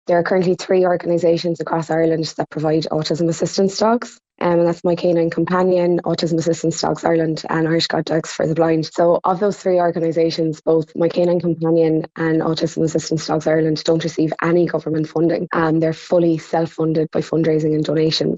She says the current lack of funding means there's limited supply and long waiting lists: